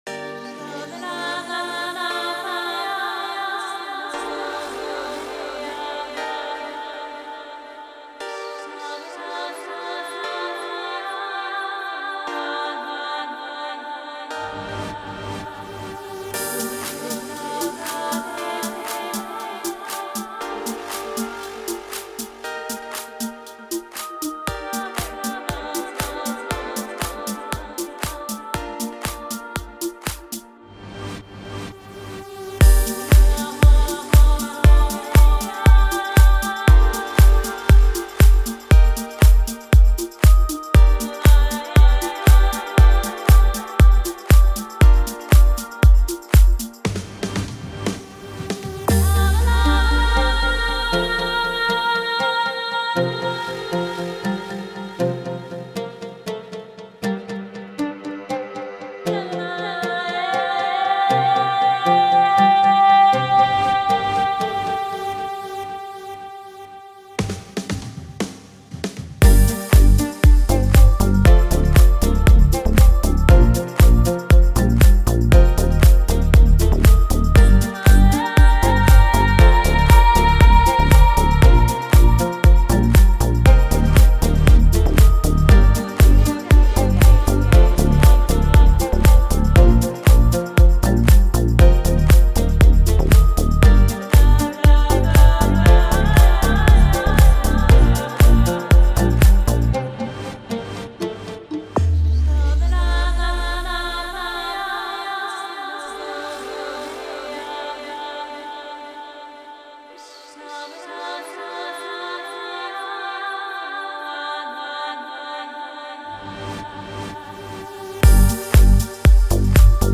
это завораживающий трек в жанре прогрессивного хауса